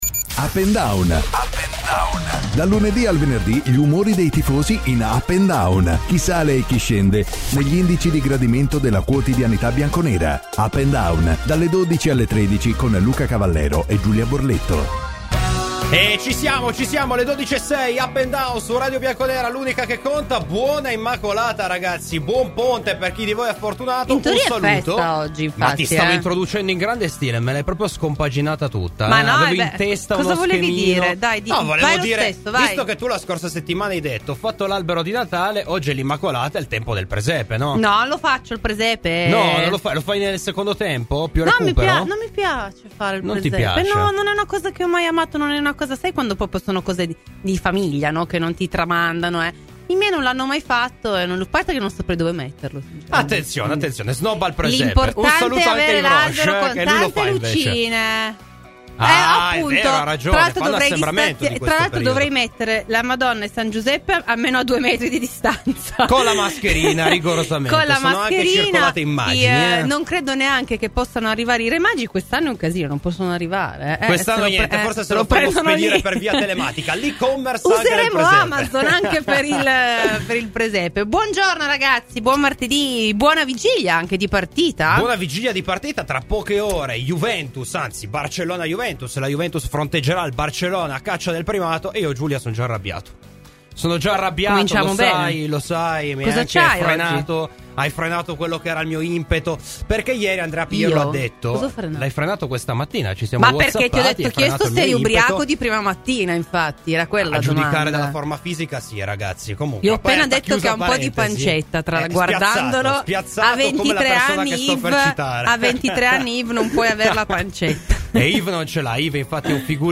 Clicca sul podcast in calce per l’intervista completa e per la trasmissione integrale.